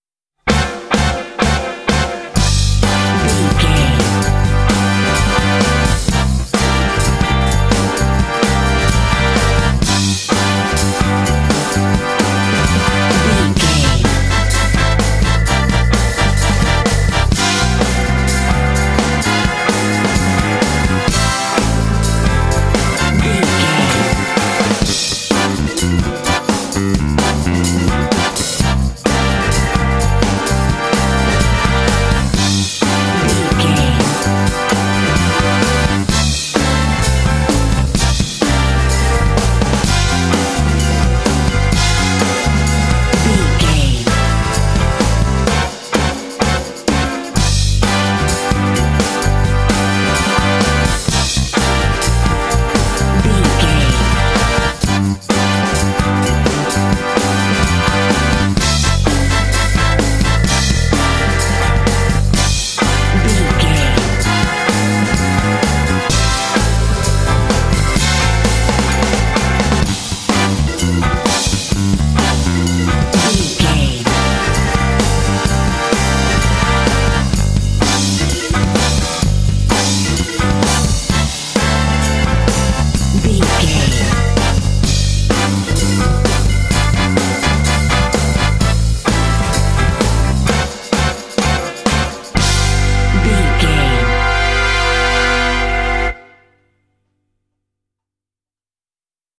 Aeolian/Minor
intense
driving
energetic
groovy
funky
electric guitar
electric organ
bass guitar
drums
brass
Funk
blues